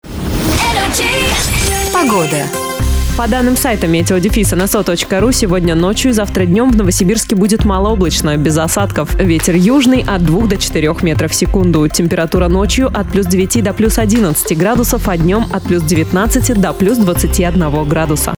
Информационная начитка